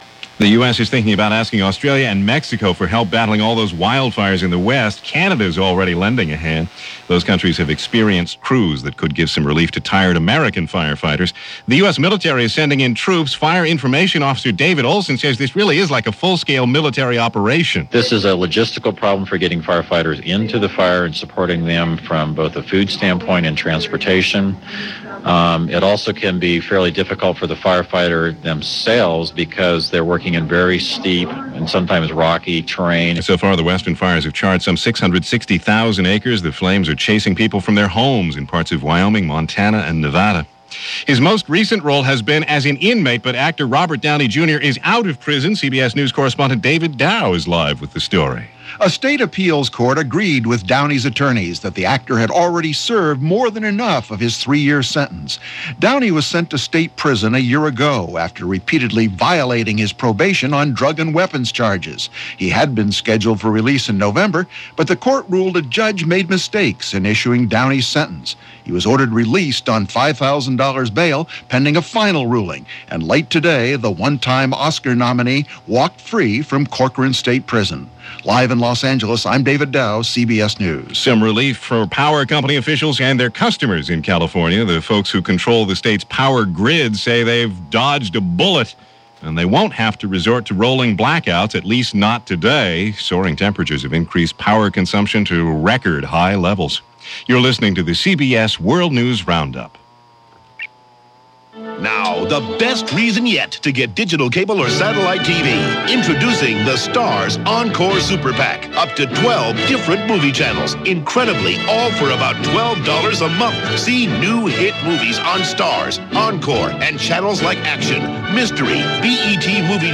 And that’s a little of what went on, this August 2nd 2000 as reported by The CBS World News Roundup; Late Edition.